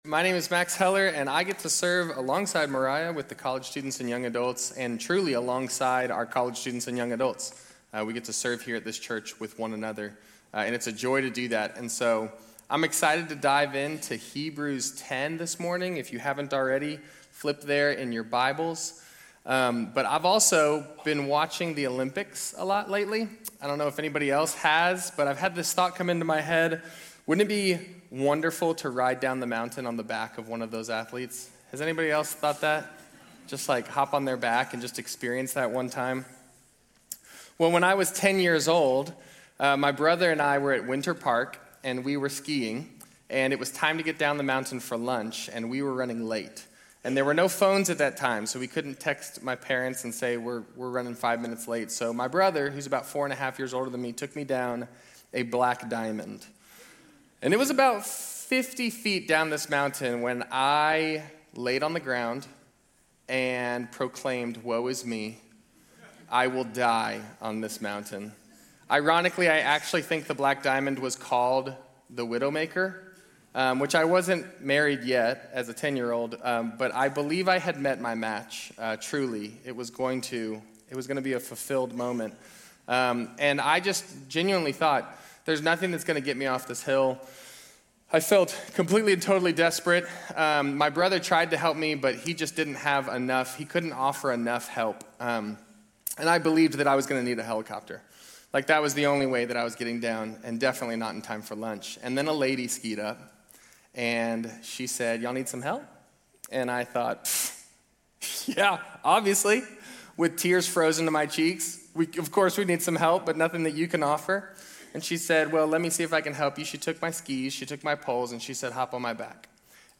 Grace Community Church University Blvd Campus Sermons 2_15 University Blvd Campus Feb 16 2026 | 00:35:00 1x 00:00 / 00:35:00 Subscribe Share RSS Feed Share Link Embed